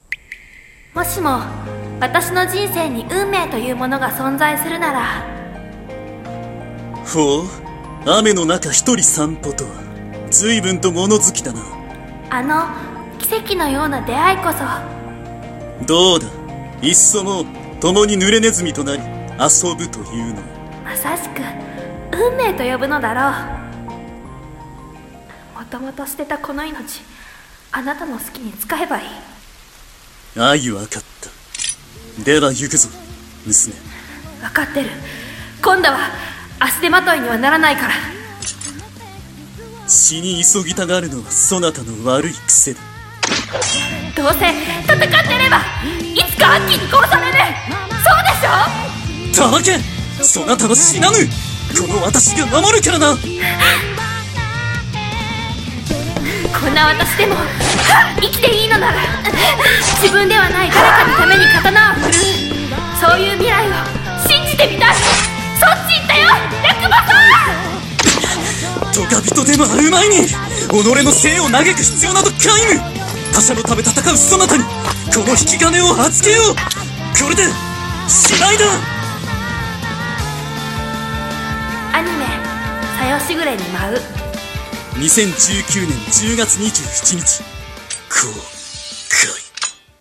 【CM風声劇台本】小夜時雨に舞う【2人用】